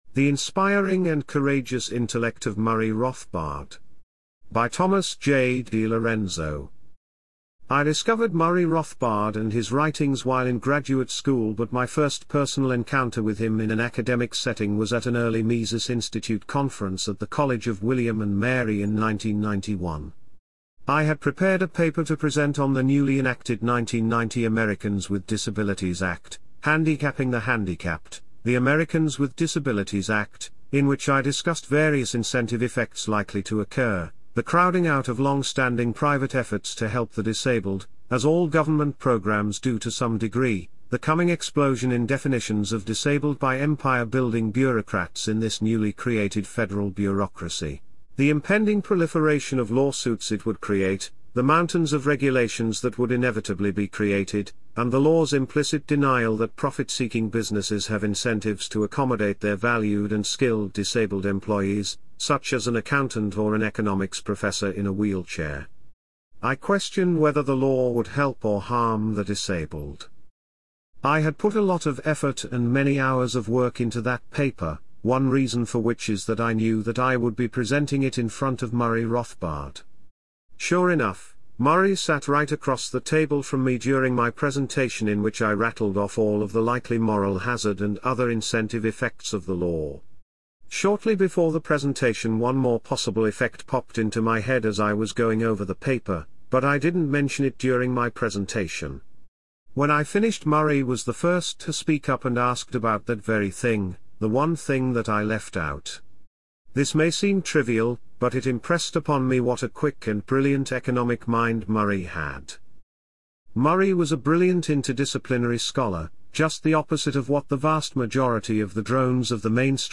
AI-assisted audio narration of the main chapters of Rothbard at 100: A Tribute and Assessment (Papinian Press and The Saif House, 2026) is available at this PFS Youtube Playlist; the mp3 files may also be downloaded in this zip file.